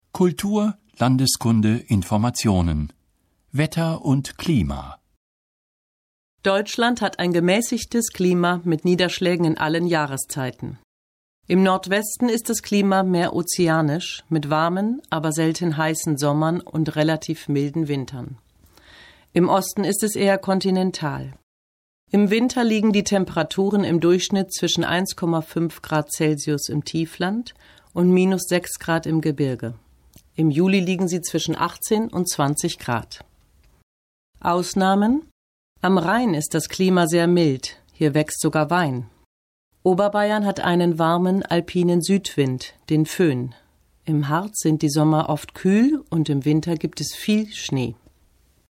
Situation 8 – Dialog: Das Wetter in Regensburg (498.0K)